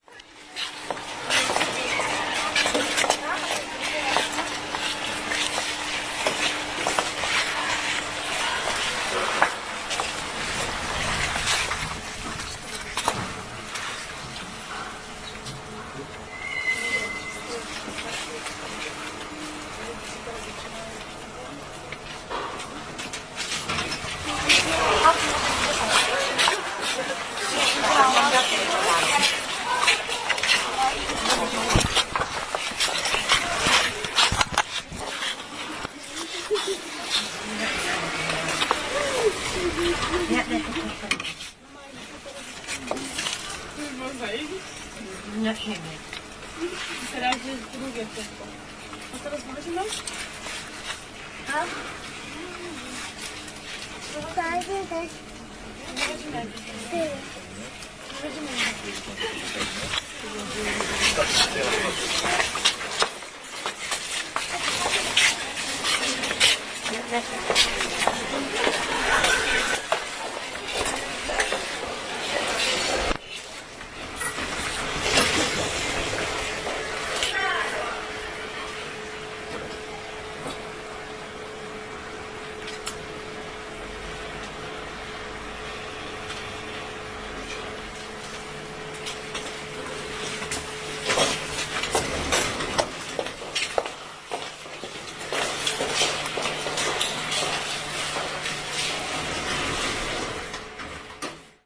描述：餐厅后方的风扇声。波兰波兹南中心的老租界院子（老市场广场：老市场街和Wroclawska街的十字路口）。
Marantz PMD 661 + shure vp88。没有处理。
标签： 波兹南 风扇 fieldrecording 庭院 波兰 噪声 城市中心
声道立体声